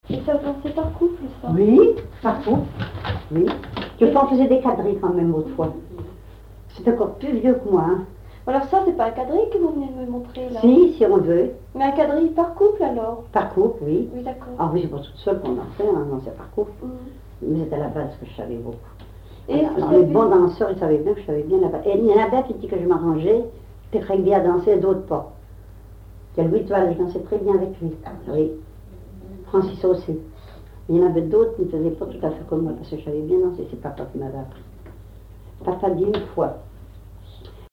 Localisation Cancale (Plus d'informations sur Wikipedia)
Catégorie Témoignage